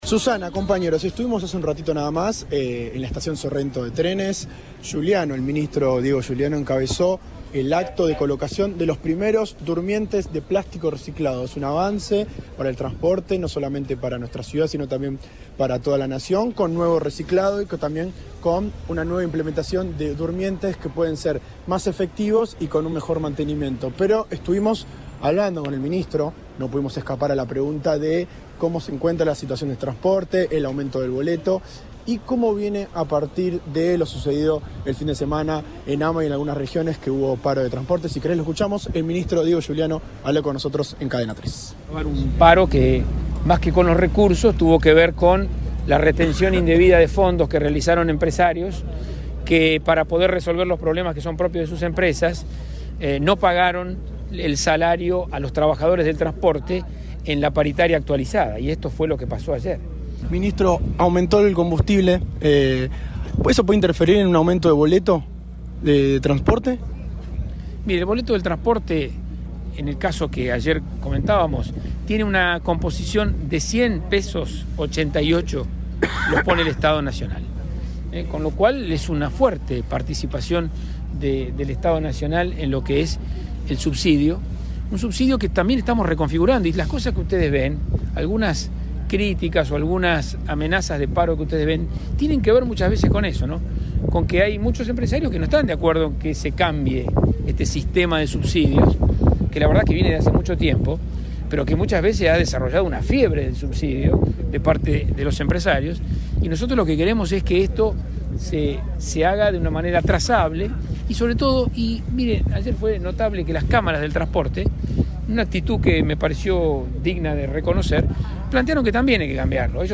Audio. El ministro de Transporte, Diego Giuliano, estuvo en Rosario y habló con Cadena 3.
En diálogo con el móvil de Cadena 3 Rosario, Giuliano afirmó que el paro que impactó en las últimas horas al transporte urbano de corta y media distancia en buena parte del país (no así Rosario), se debió “a la retención indebida de fondos por parte de empresarios”.